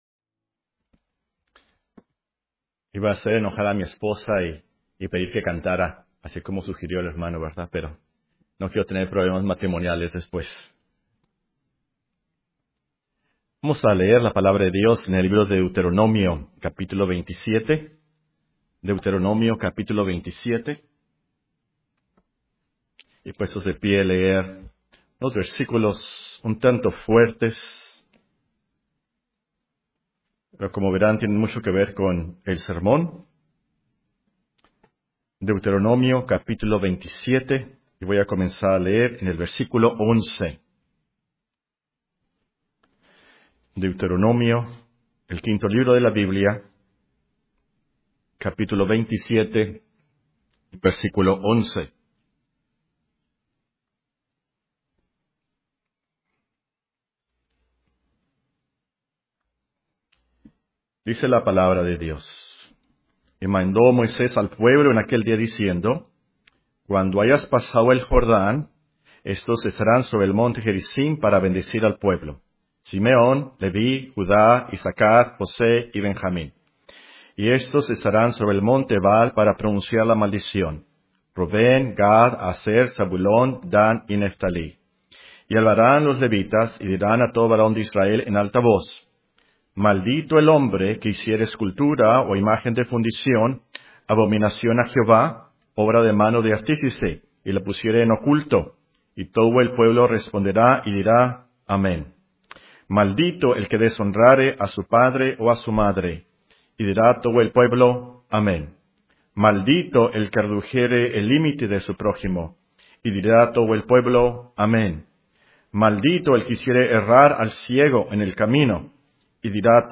Sermones Biblicos Reformados en Audio, porque la Fe viene por el Oir